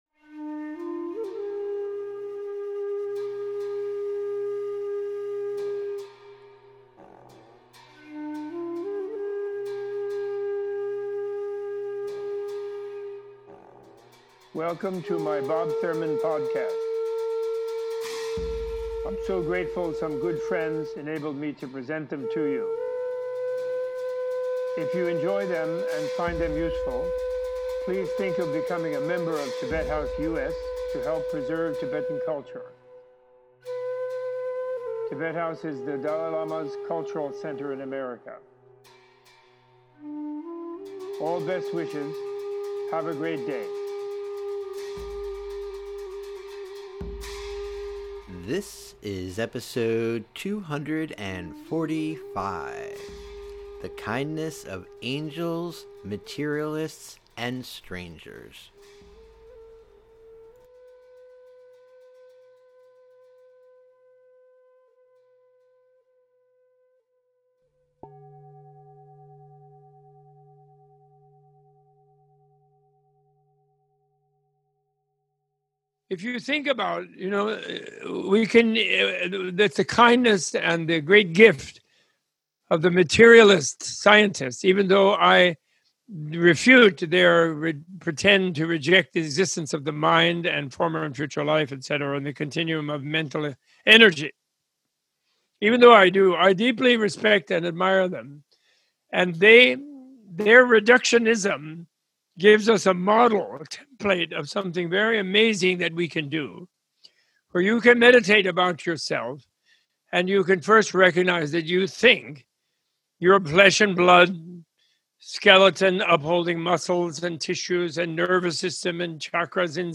Opening with an extended message of gratitude to modern scientists, Professor Thurman in this episode explores the power of kindness and the centrality of compassionate wisdom in transformation through reading key passages from the miss titled “Tibetan Book of the Dead”. Robert A.F. Thurman gives a close line reading and recitation of his classic translation of the “Tibetan Book of the Dead” for listeners of all faiths, backgrounds or experience.